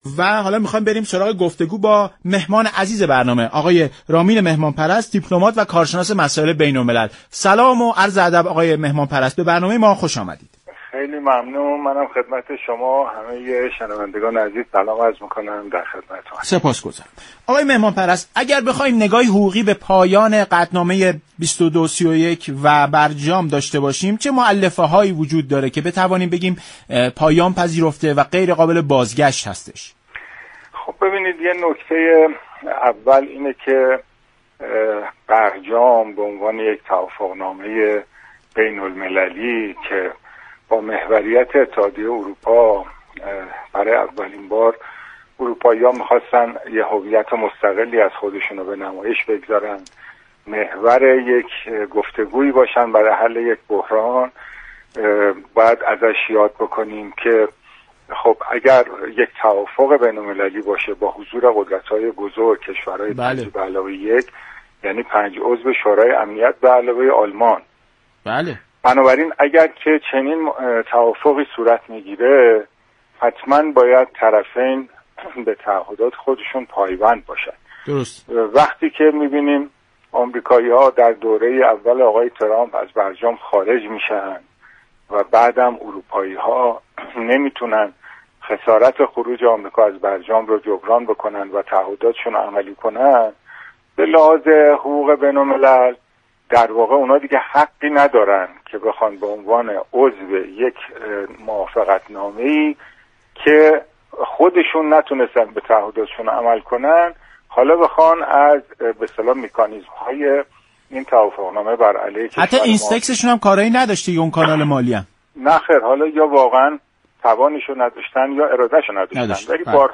رامین مهمان‌پرست دیپلمات و كارشناس مسائل بین‌الملل گفت: وقتی طرف‌های اروپایی توان جبران خسارات خروج آمریكا از برجام را ندارند به لحاظ حقوق بین‌المللی نمی‌توانند از مكانیزم‌های موجود علیه ایران را استفاده كنند.